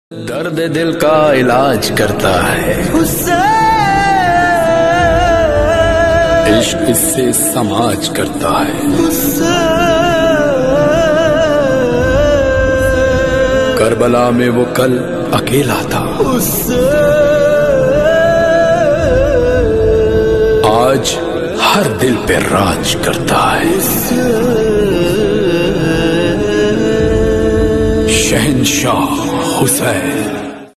Islamic Ringtones